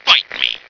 flak_m/sounds/male2/int/M2biteme.ogg at efc08c3d1633b478afbfe5c214bbab017949b51b